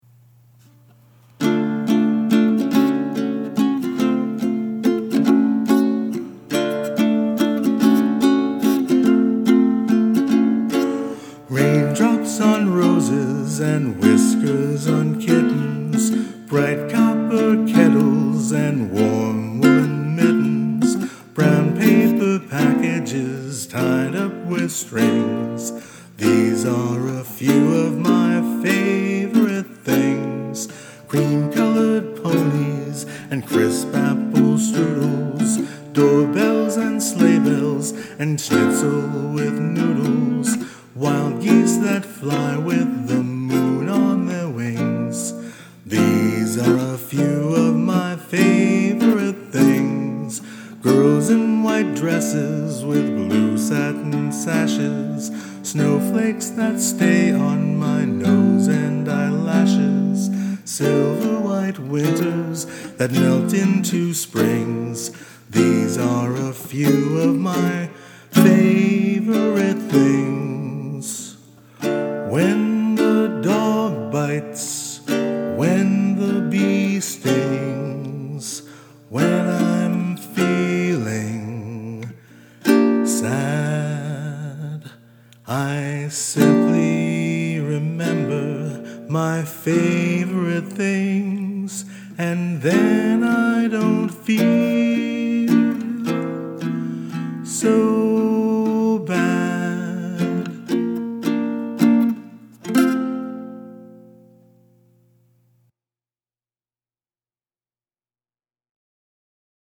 Filed under Music, Personal, Ukelele, Uncategorized